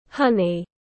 Mật ong tiếng anh gọi là honey, phiên âm tiếng anh đọc là /ˈhʌn.i/
Honey /ˈhʌn.i/